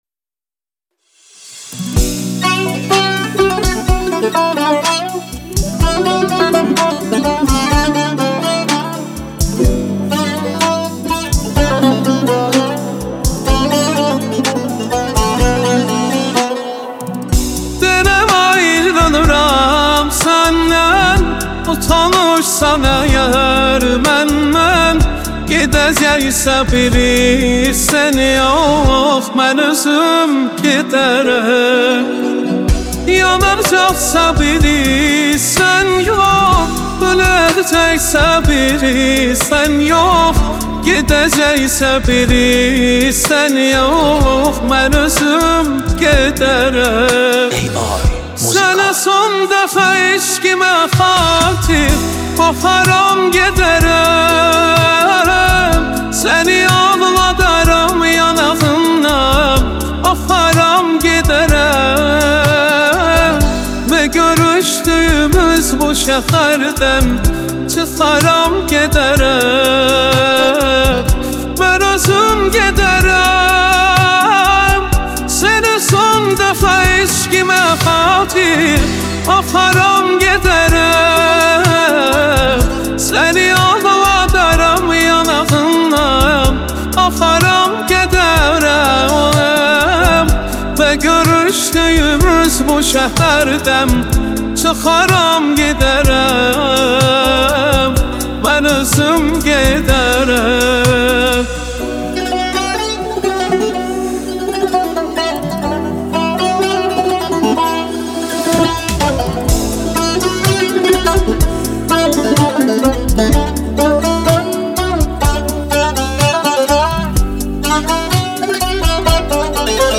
اهنگ پاپ خارجی
اهنگ غمگین